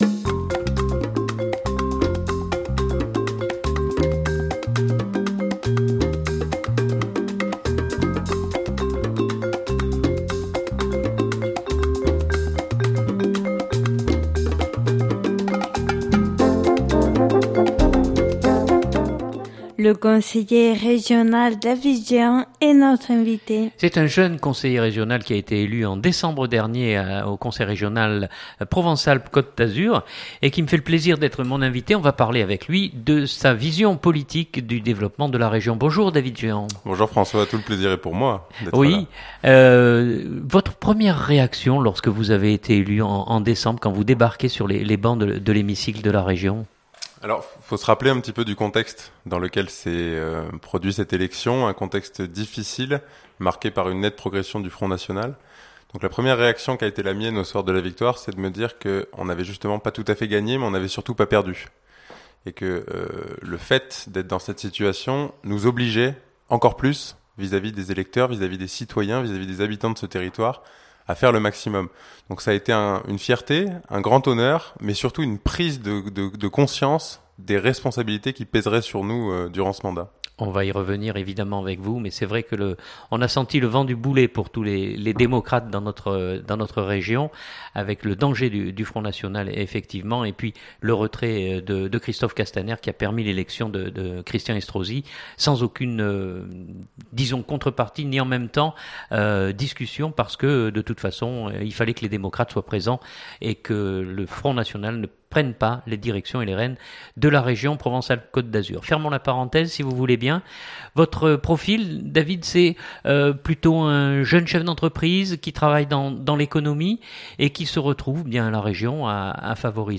Journal du 2016-09-15 Interview David Géhant.mp3 (11.27 Mo)